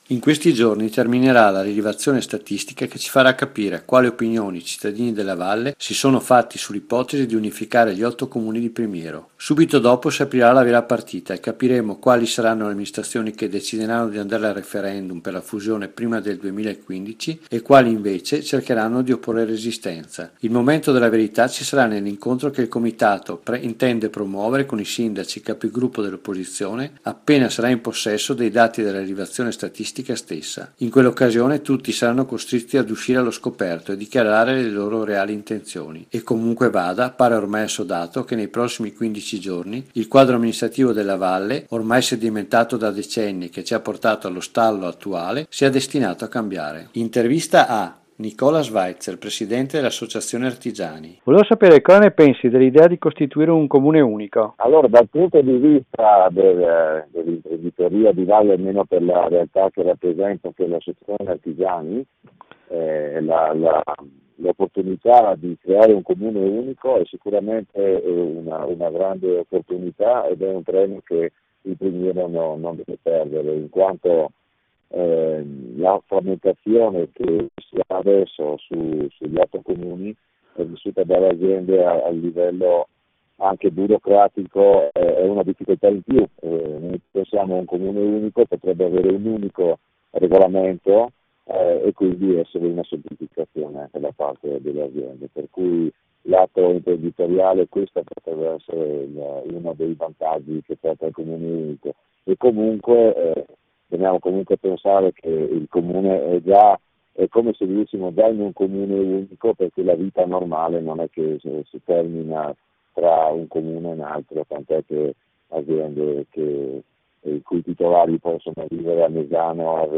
Interviste per Un Primiero Meno Diviso